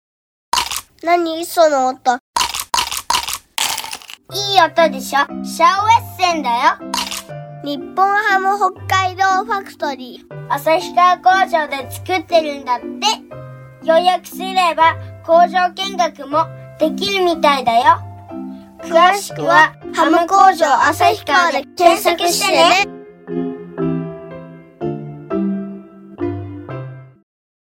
街頭放送CMの専門家
音の広告　街頭放送